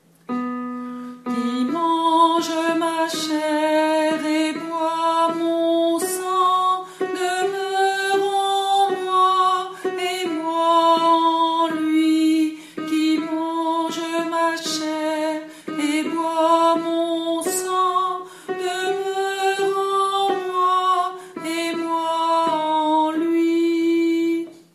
Tutti Soprano Alto Tenor Basse